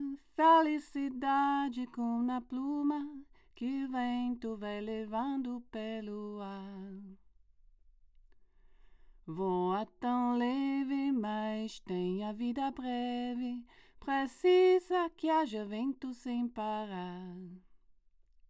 To my ears the NOS Telefunken tube makes the mic more mid-focused, the low frequencies are still clear, warm and big, the highs are a little less bright and sharp but the mid presence makes the mic cut through the mix more.
All clips were recorded via the preamps on my UA Apollo interface, I’ve endeavoured to maintain the same conditions and distances between mic and source for each comparison, but there may be small variations.
Female voice
NOS Telefunken E88CC
rode-k2-nos-telefunken-f-vox.wav